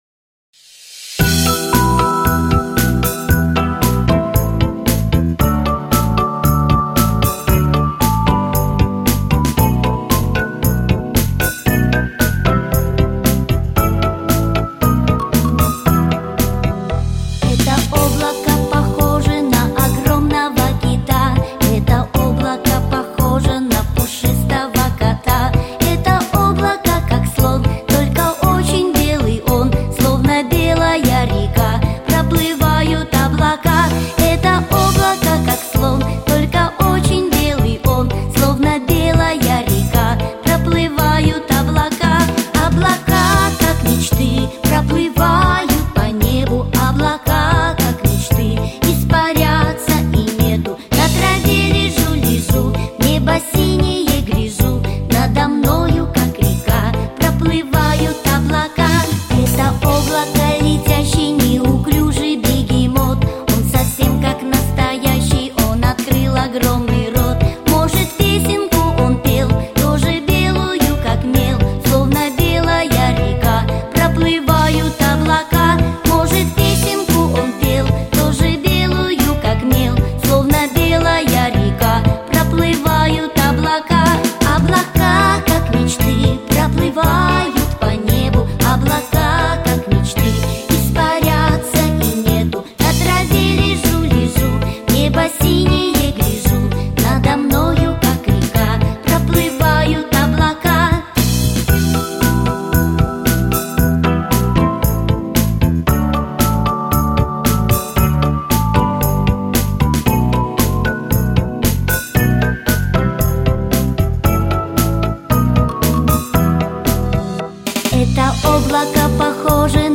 Аудиокнига Детский праздник | Библиотека аудиокниг
Aудиокнига Детский праздник Автор Виктор Ударцев Читает аудиокнигу Актерский коллектив.